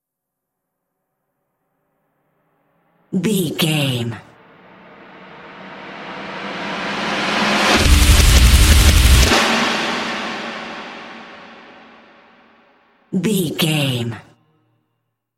Aeolian/Minor
drums
electric guitar
bass guitar
pop rock
hard rock
lead guitar
aggressive
energetic
intense
powerful
nu metal
alternative metal